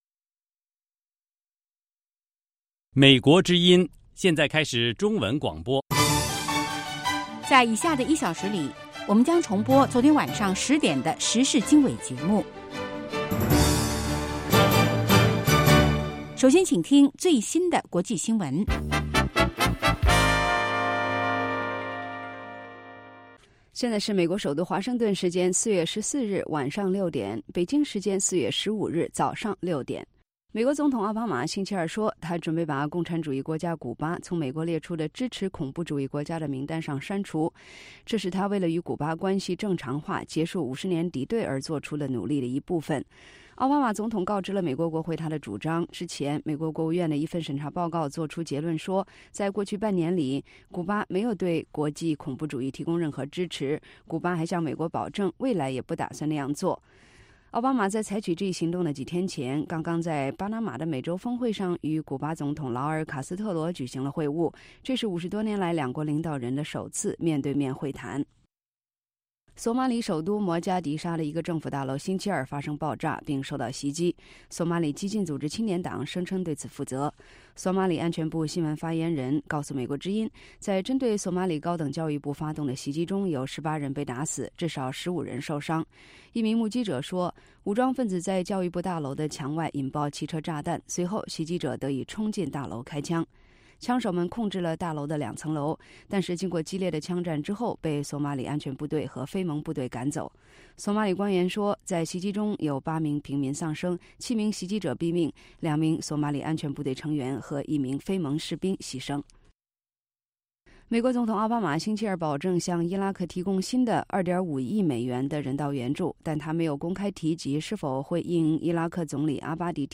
北京时间早上6-7点广播节目 这个小时我们播报最新国际新闻，并重播前一天晚上10-11点的时事经纬节目。